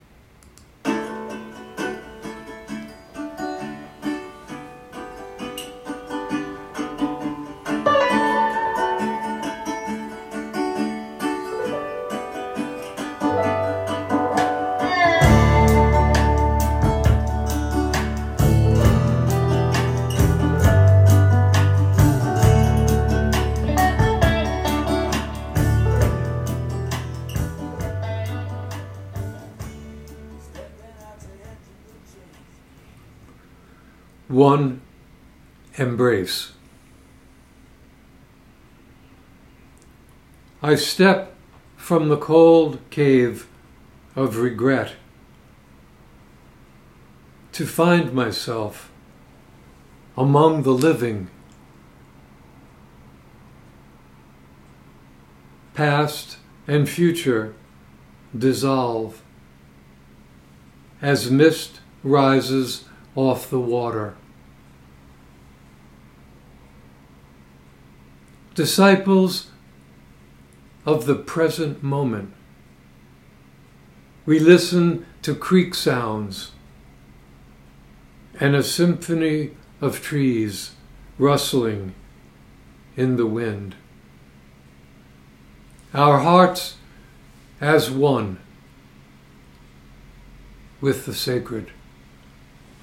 Reading of One Embrace with music by Dire Straits.